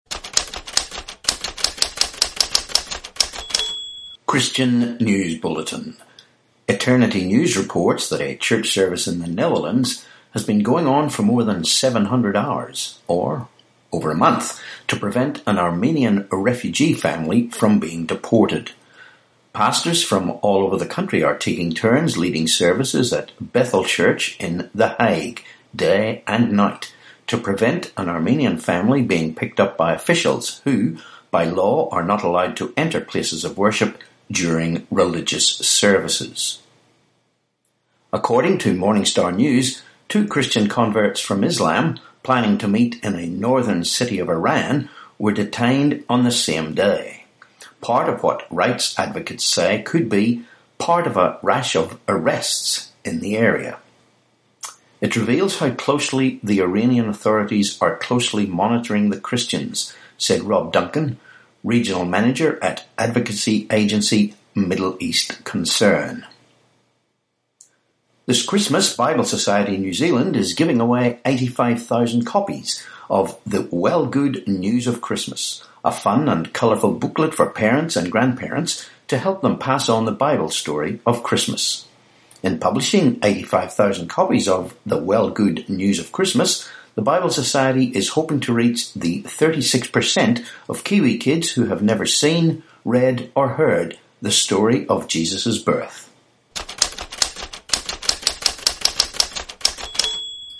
Christian News Bulletin, podcast